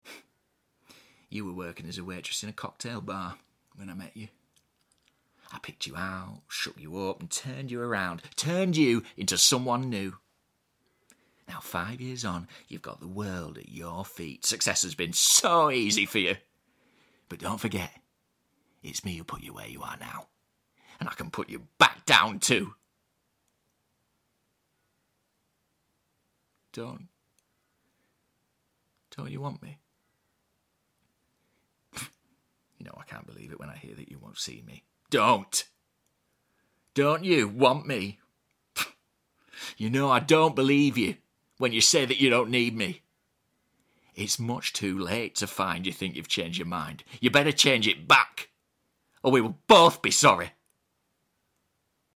🎭 Dramatic Monologue: Don’t You